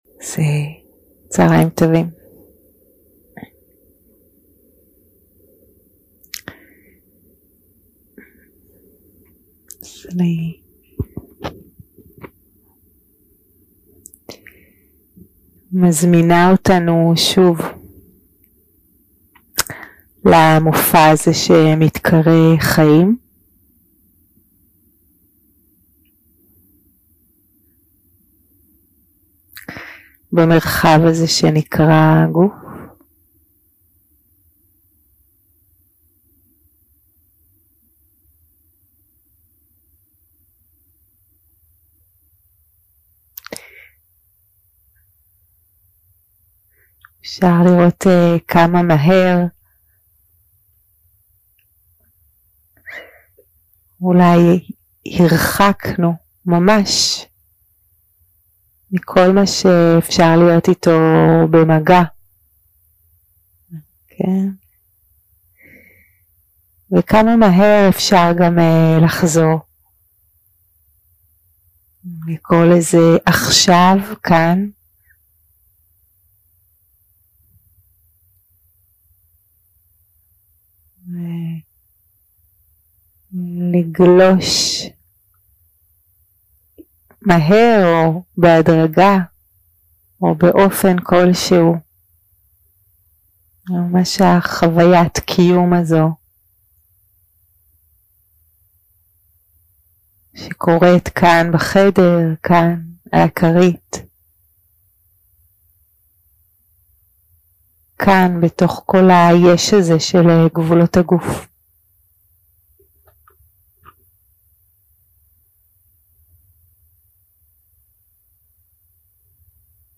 יום 2 – הקלטה 4 – אחר הצהריים – מדיטציה מונחית - לפגוש את החוויה
יום 2 – הקלטה 4 – אחר הצהריים – מדיטציה מונחית - לפגוש את החוויה 0:00 0:00 סוג ההקלטה: Dharma type: Guided meditation שפת ההקלטה: Dharma talk language: Hebrew